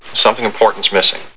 The Fly Movie Sound Bites